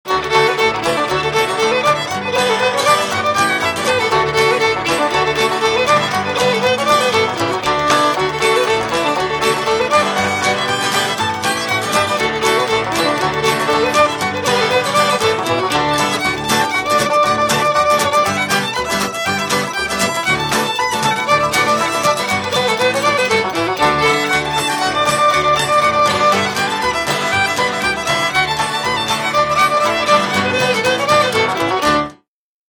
Но иначе не споешь рилл!
_--irishrea_p-w.mp3